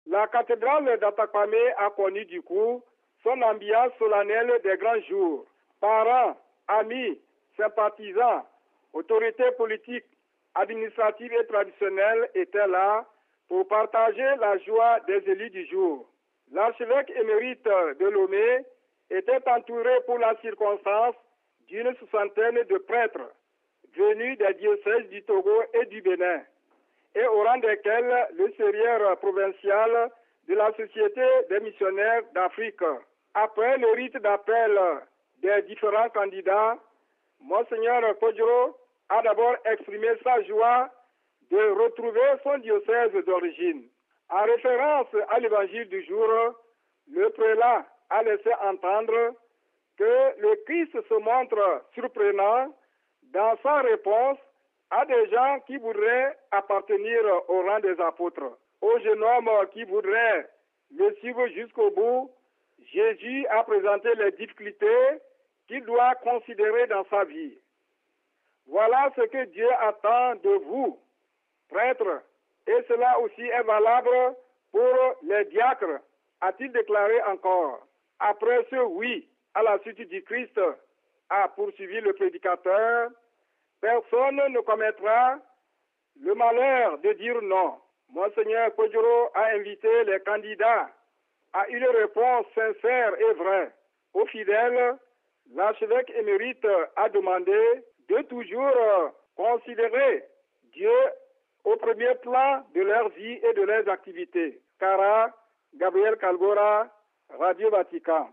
Le 25 septembre dernier la famille de Dieu qui est dans le diocèse d'Atakpamé a accueilli dans son clergé trois nouveaux prêtres dont un missionnaire de la Société des Missions Africaines, et cinq diacres, au cours d'une messe solennelle présidée par Mgr Philippe Kpodzro, archevêque émérite de Lomé, en remplacement de l'ordinaire du lieu, Mgr Nicodème Barrigah, empêché. Notre collaborateur local en donne des détails: RealAudio